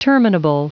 Prononciation du mot terminable en anglais (fichier audio)
Prononciation du mot : terminable